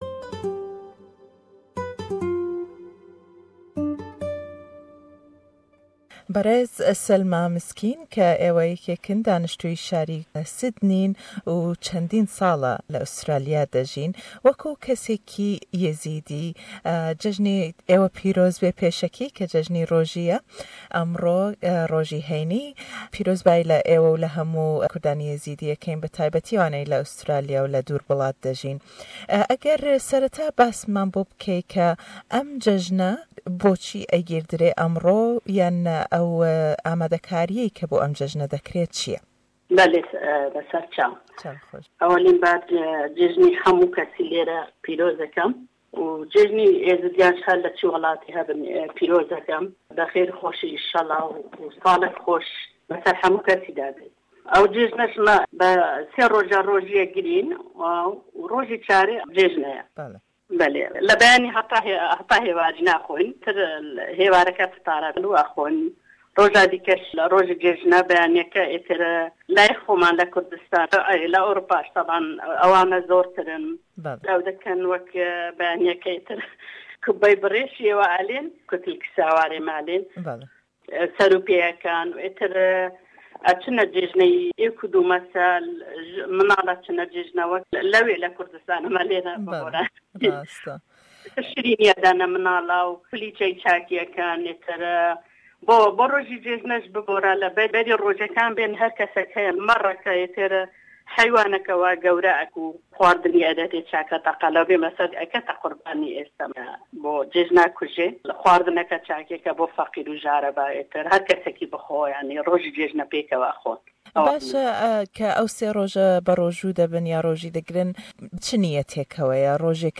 Le em hevpeyvîne